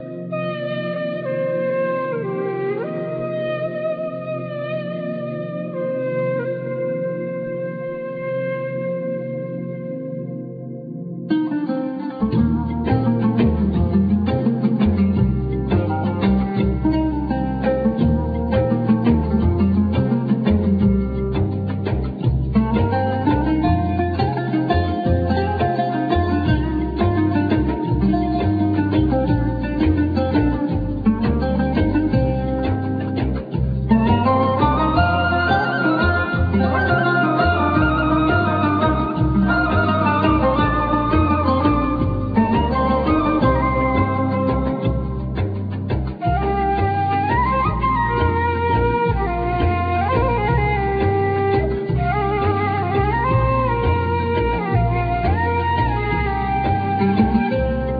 Ney
Oud,Vocals
Percssions
Kalimba
Bass,Synthsizer,Percussions,Mandola,12 string guitar
Violin